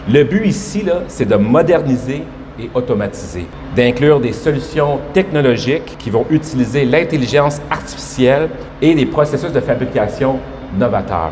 Le montant a été annoncé ce lundi en point de presse.